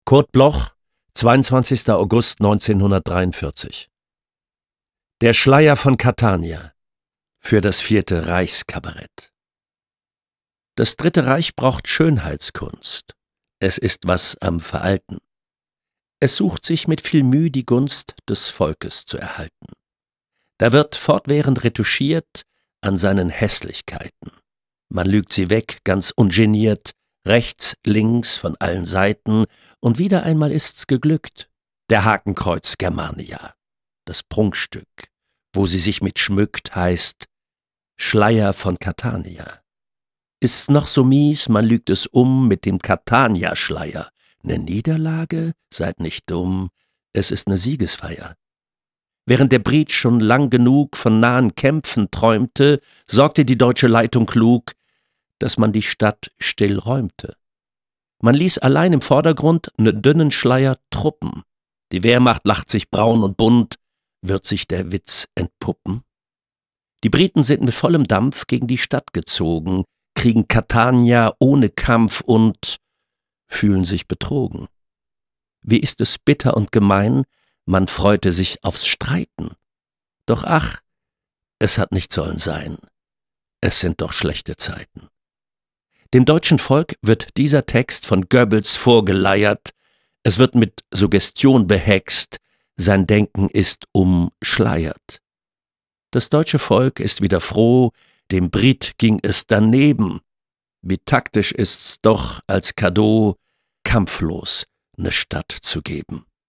De sluier van Catania voorgedragen door Wolfram Koch